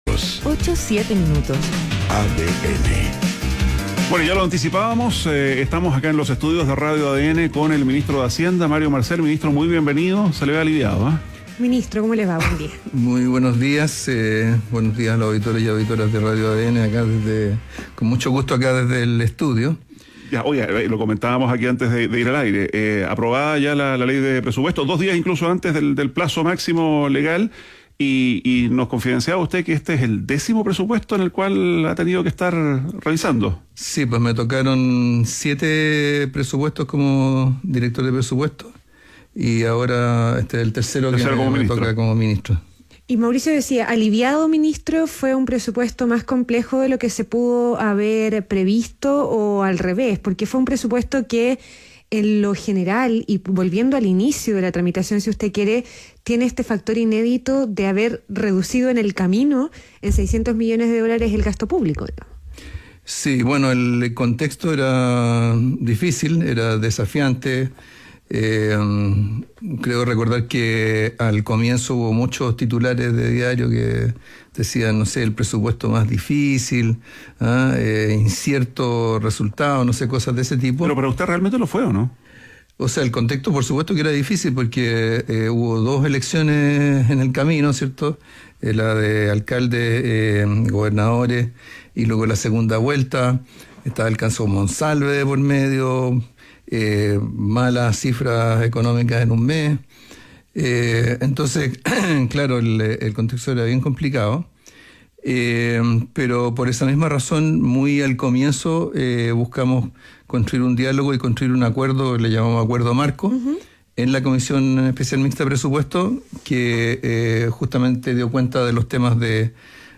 ADN Hoy - Entrevista a Mario Marcel, ministro de Hacienda